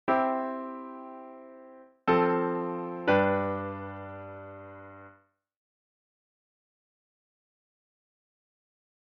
Se escuchan 3 acordes. El primero es la triada de la tonalidad. Los que siguen son los de la cadencia.
Semi+Iv+A+V 2+C (audio/mpeg)